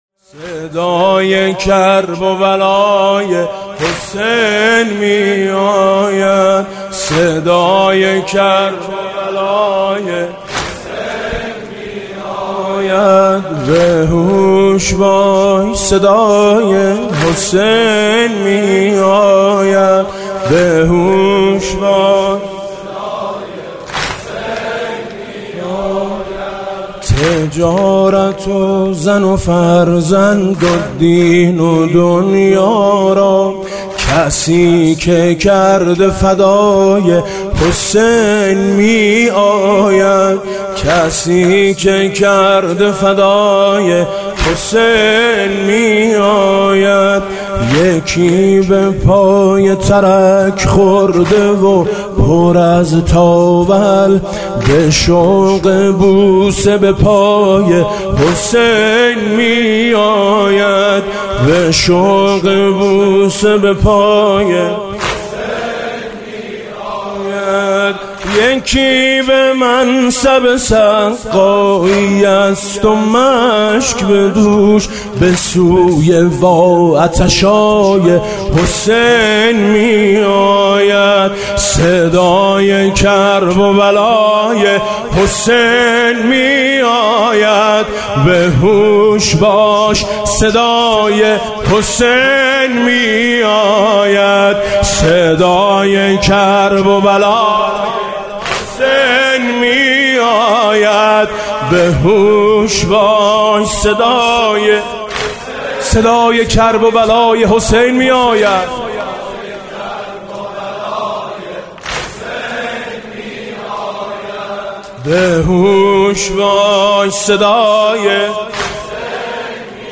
16مهر 98 - واحد -صدای کرب و بلای حسین می آید